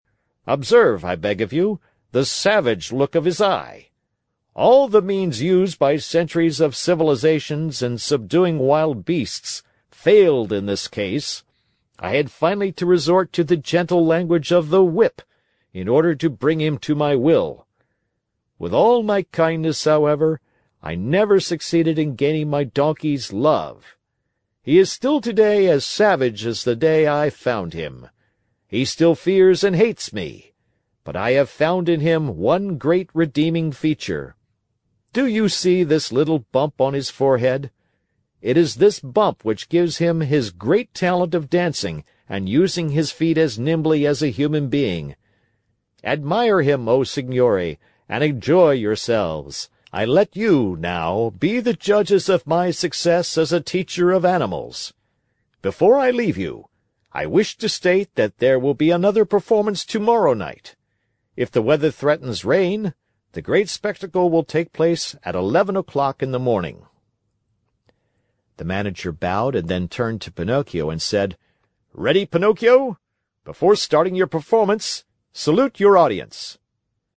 在线英语听力室木偶奇遇记 第135期:匹诺曹登台献艺(7)的听力文件下载,《木偶奇遇记》是双语童话故事的有声读物，包含中英字幕以及英语听力MP3,是听故事学英语的极好素材。